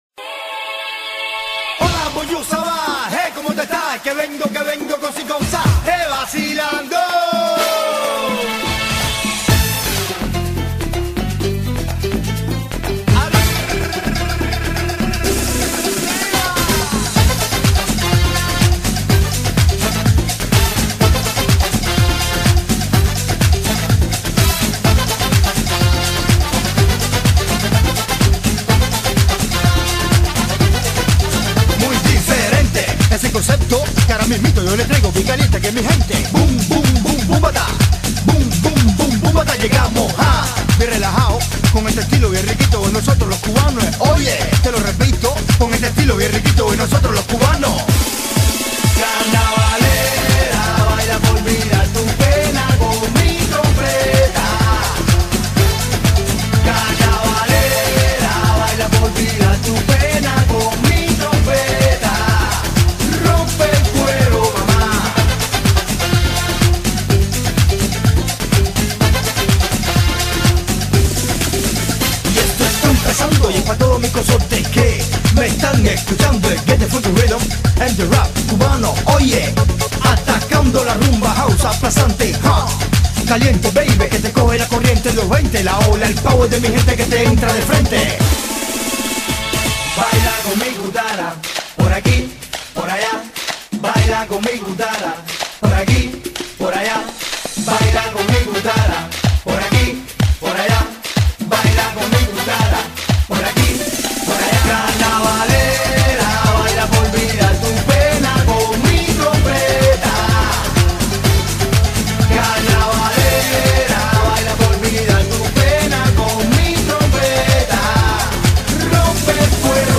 Бразильская музыка